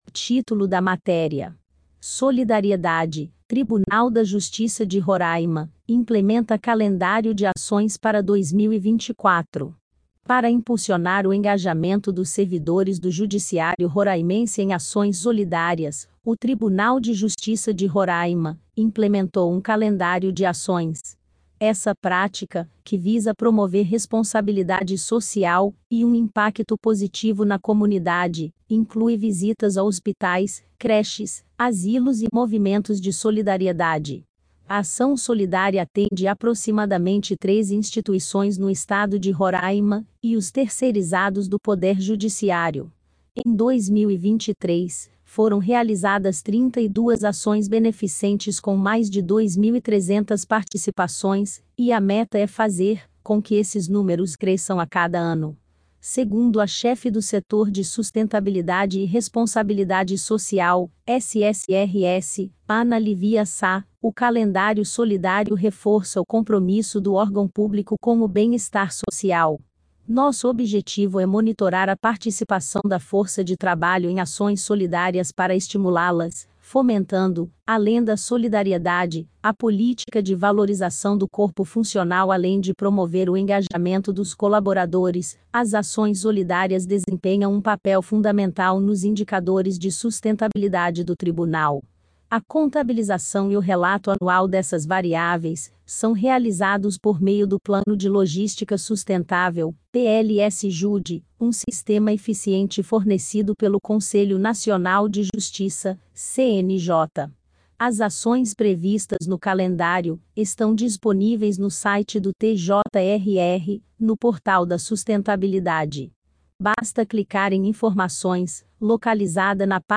Acompanhe a matéria tambem em audio
SOLIDARIEDADE_IA.mp3